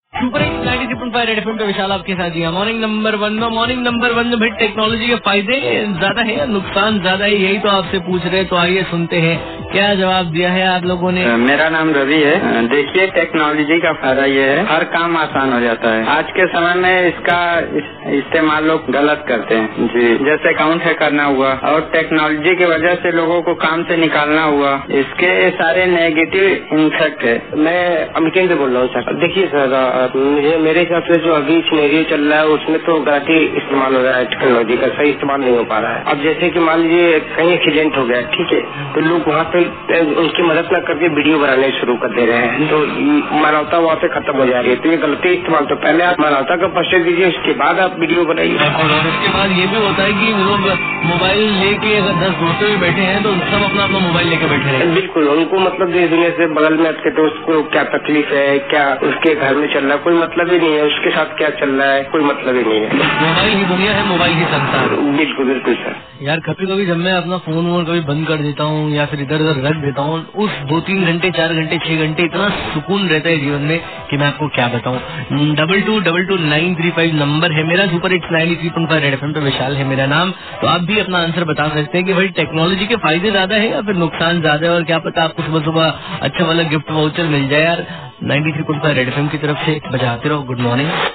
CALLER